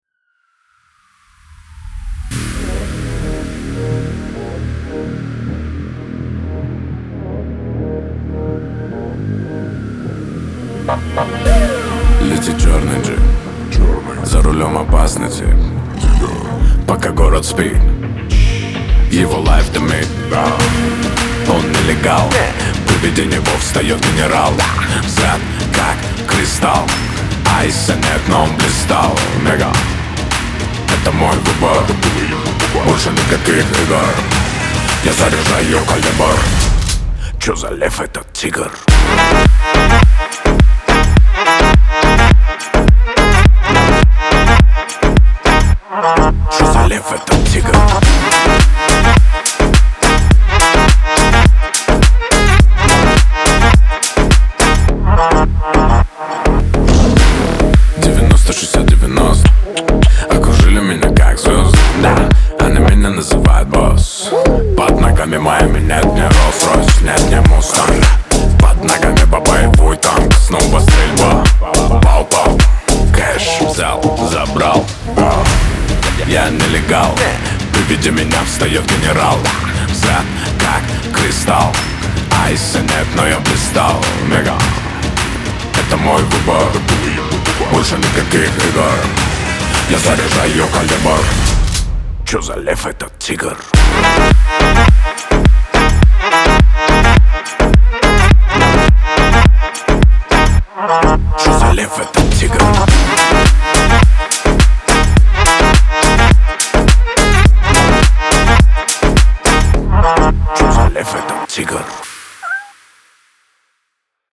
Громкие рингтоны , поп